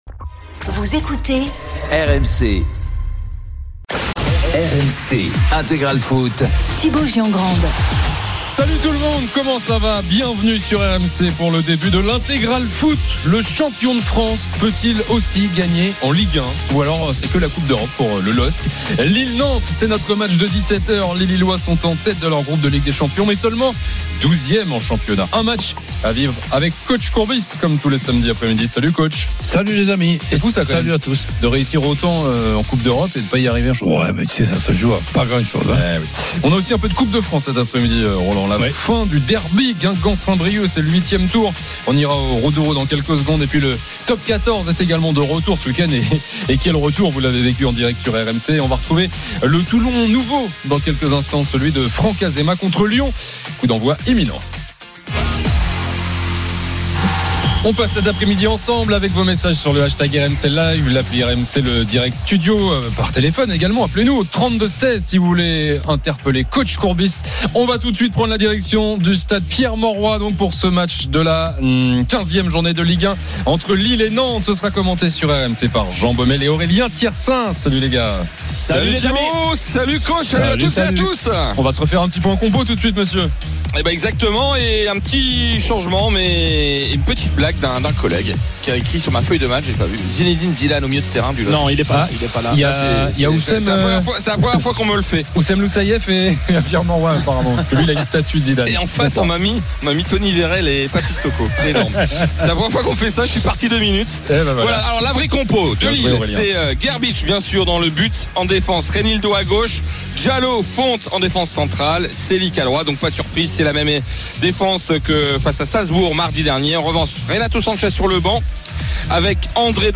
Tous les matchs en intégralité, sur RMC la radio du Sport.